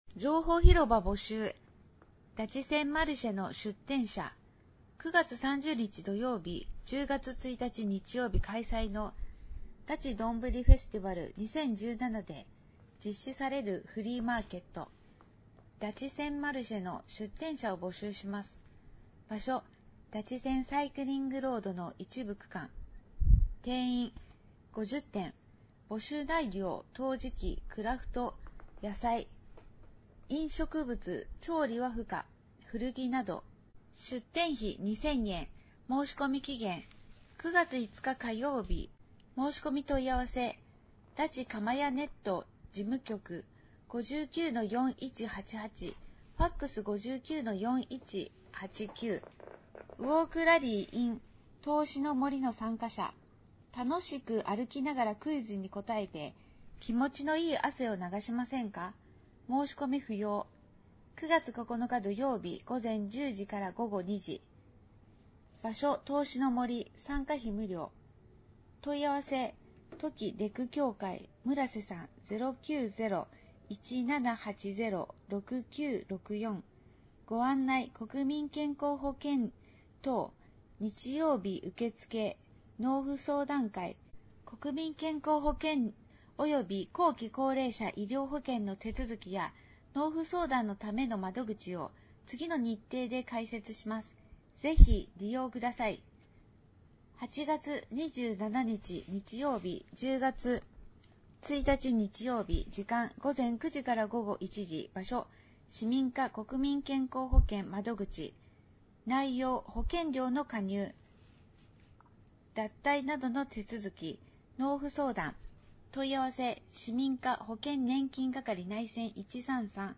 音声欄に表示があるものは、「声の広報」として、音声にてお聴きになれます。